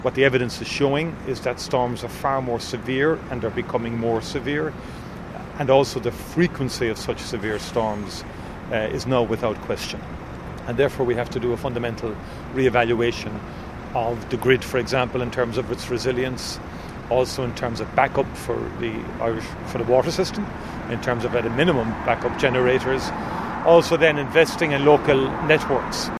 Taoiseach Micheal Martin says the focus will be on how to improve the energy grid: